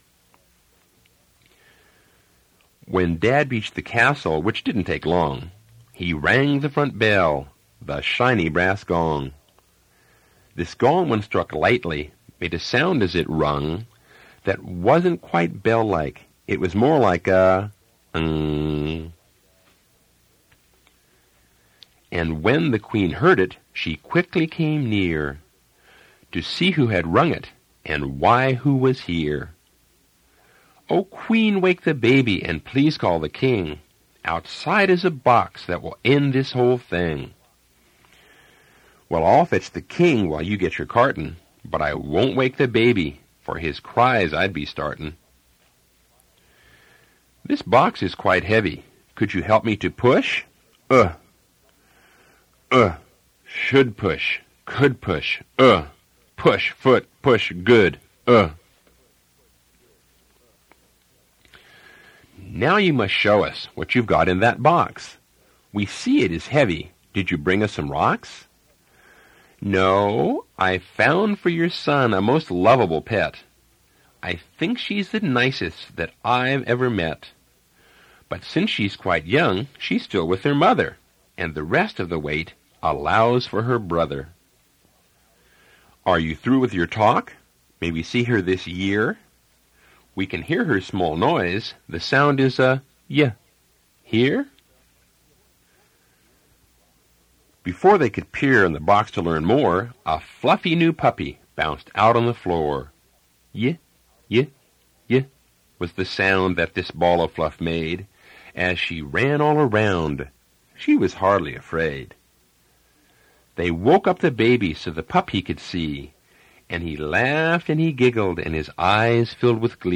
Reading of Dekodiphukan